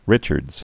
(rĭchərdz), Ellen Swallow 1842-1911.